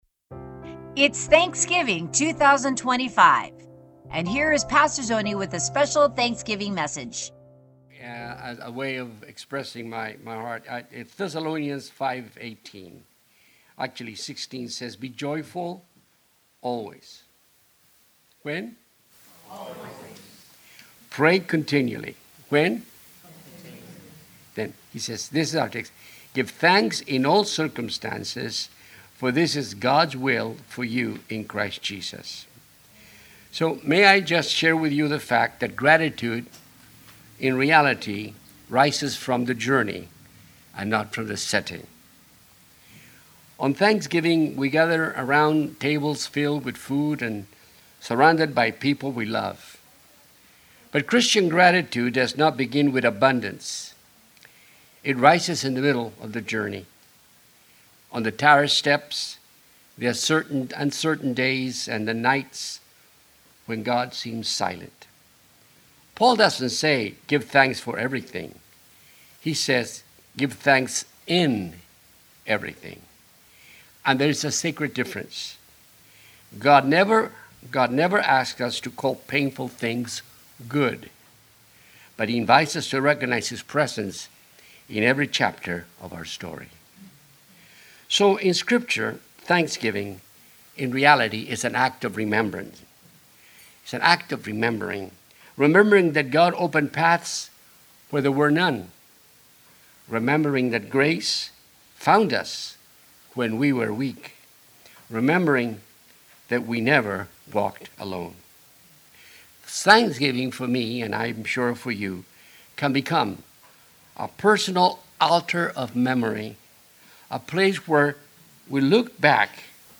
Sermons | Beverly Boulevard Foursquare Church
Thanksgiving Eve Service 2025